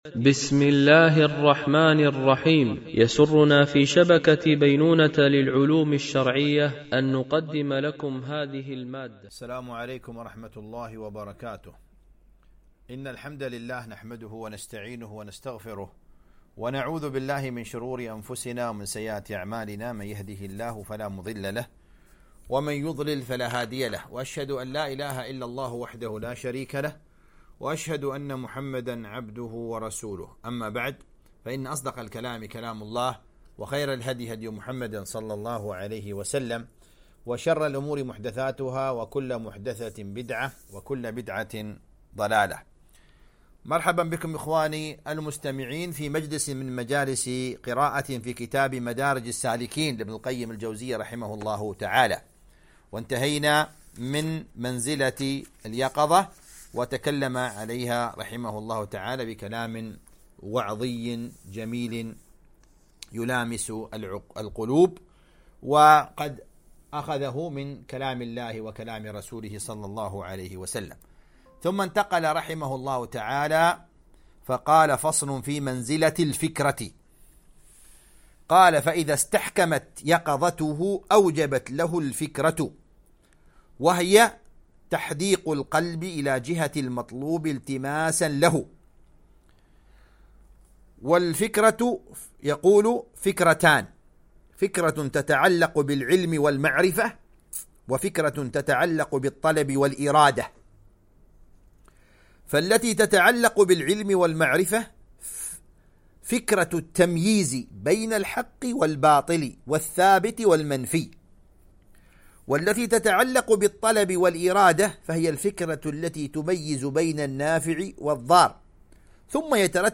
قراءة من كتاب مدارج السالكين - الدرس 20
Mono